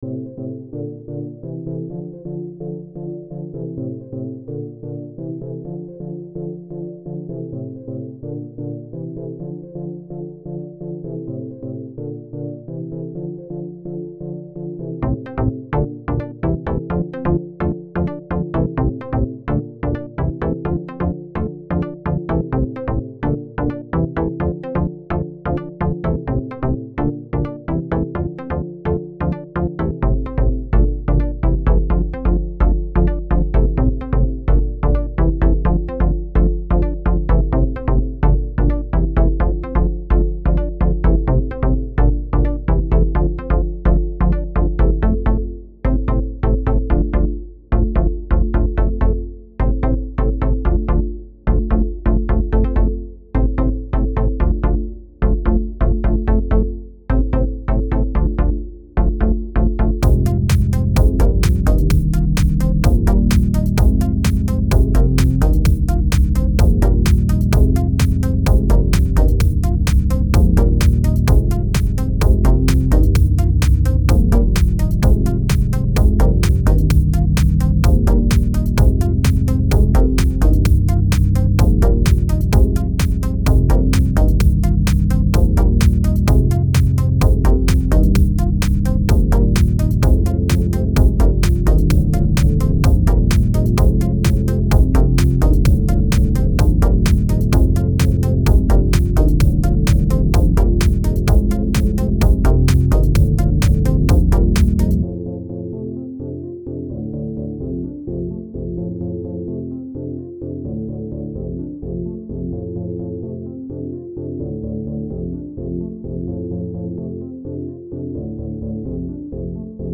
Home > Music > Beats > Electronic > Bright > Running